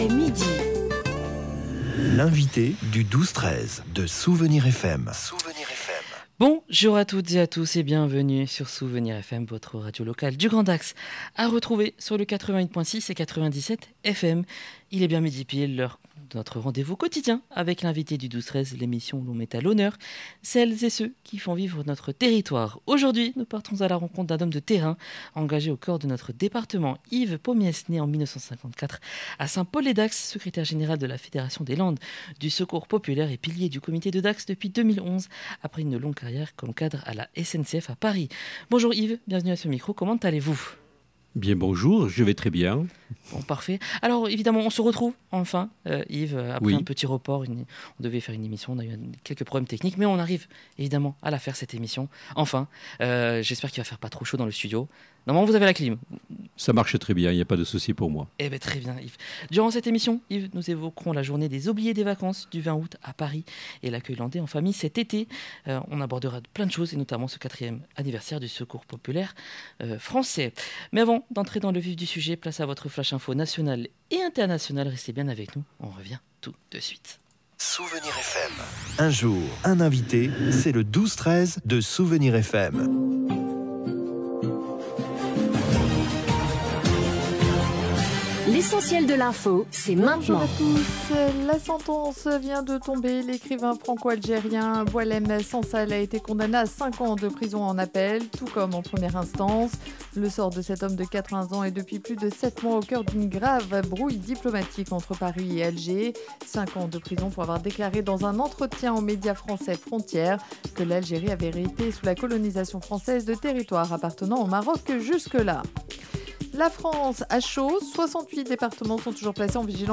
Un entretien clair et inspirant mettant en lumière le rôle essentiel de la solidarité territoriale dans les Landes.